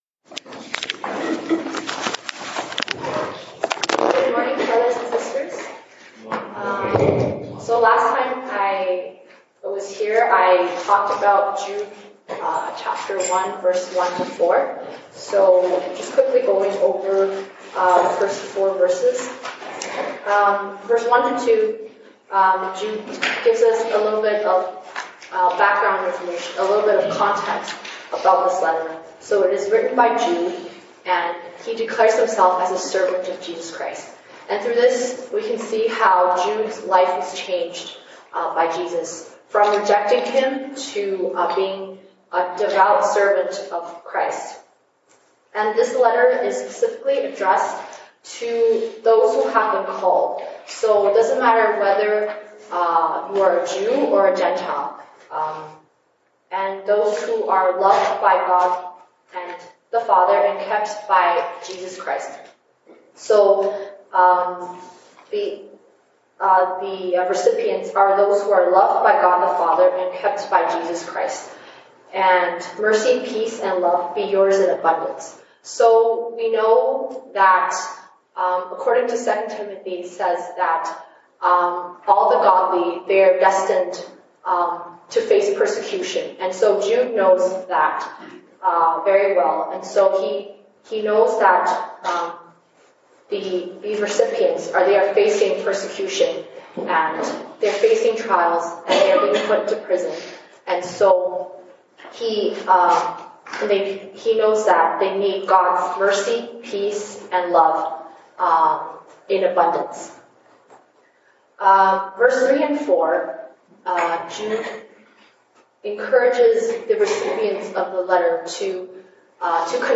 西堂證道 (英語) Sunday Service English: 猶大書 Jude 1:5-7